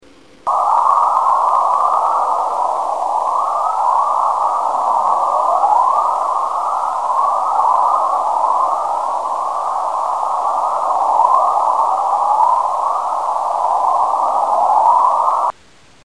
Auroral Chorus
Auroral_chorus.mp3